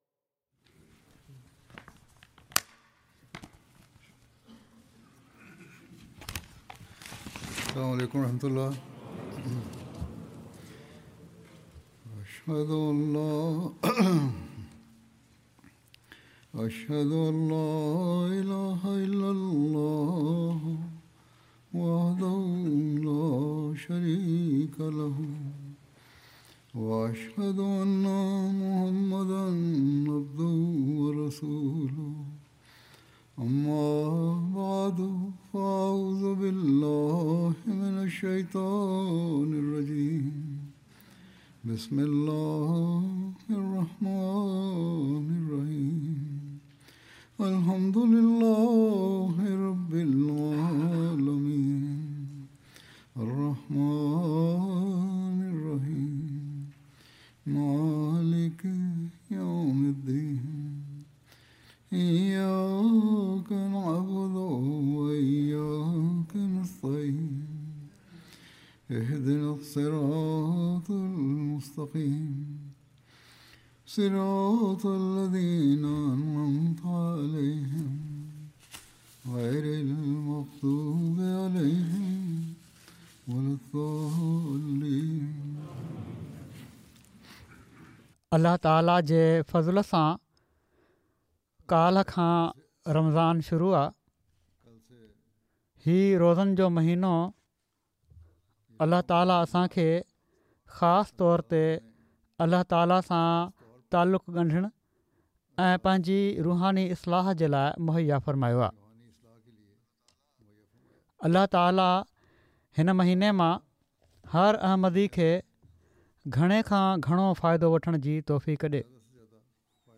Sindhi translation of Friday Sermon